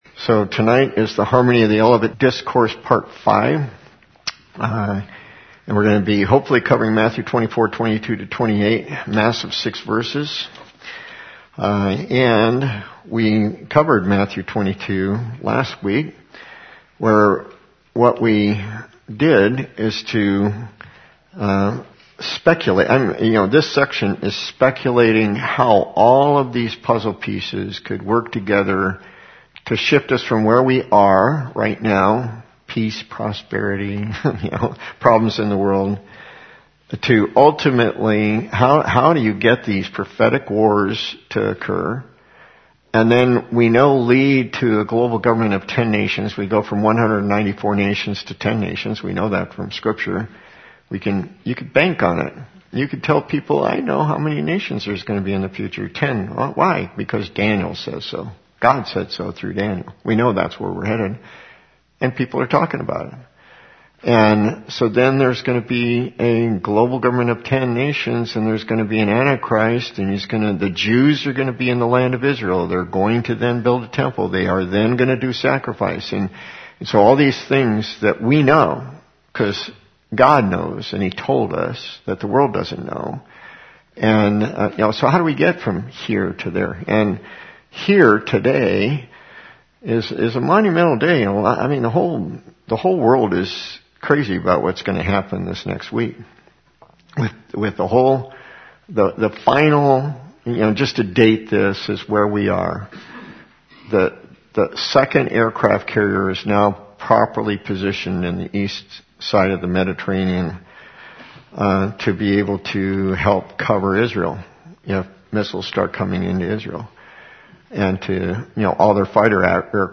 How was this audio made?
The audio file includes about a ten minute intro that is missing from the video file.